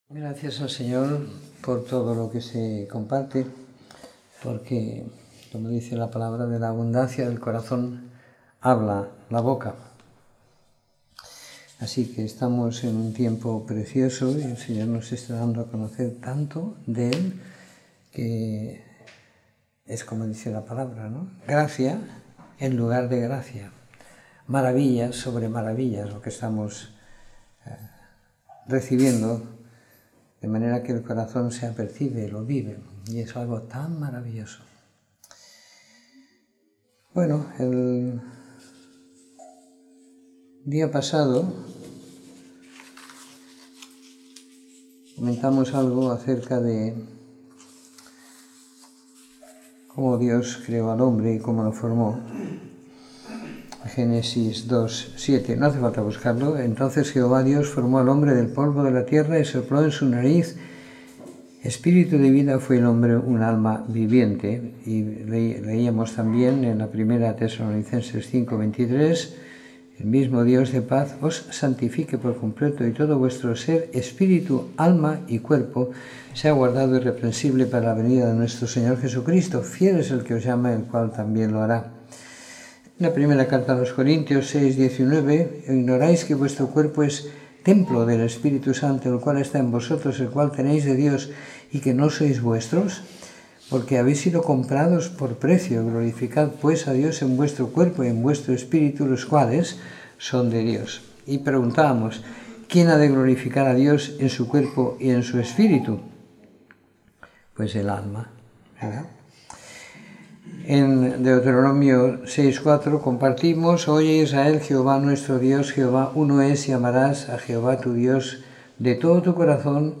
Domingo por la Tarde . 12 de Febrero de 2017